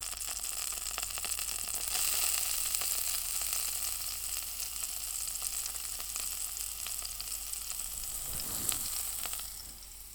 sizzling.wav